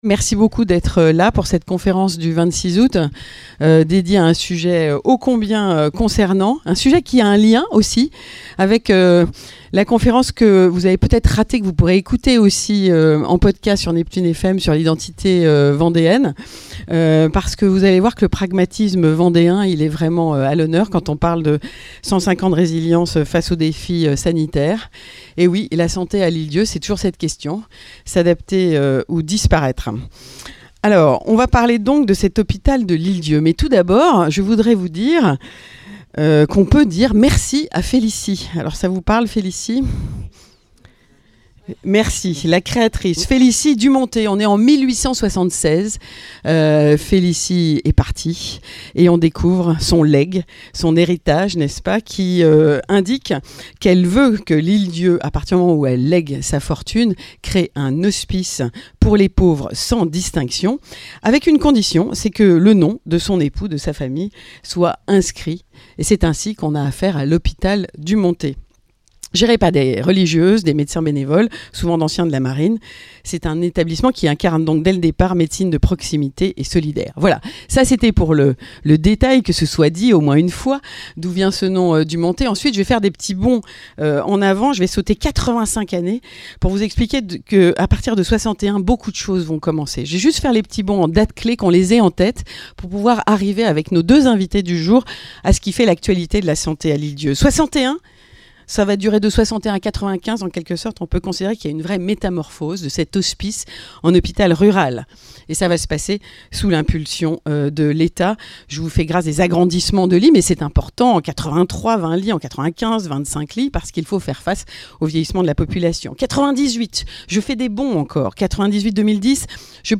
Le nombreux public présent a pu poser des questions et livrer de précieux témoignages.